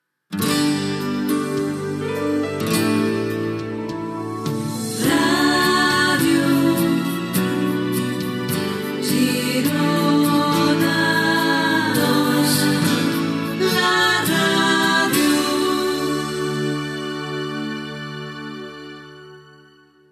Dos indicatius de l'emissora